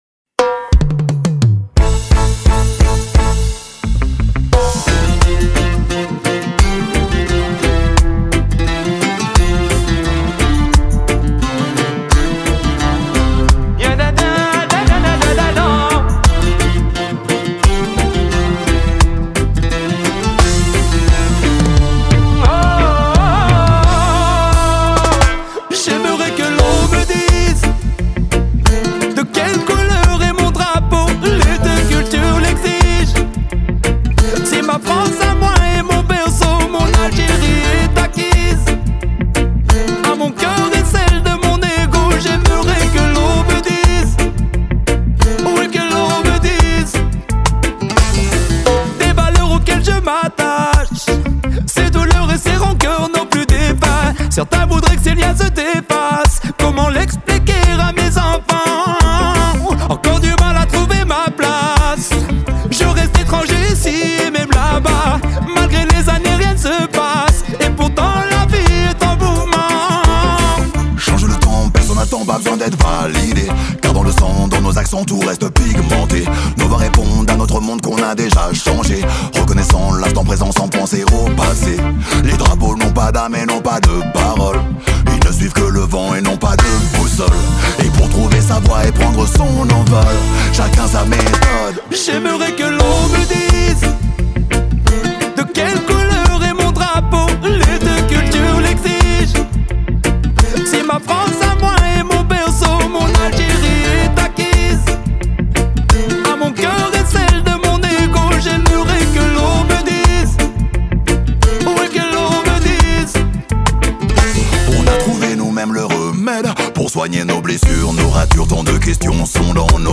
Avec de nombreux extraits du nouveau disque…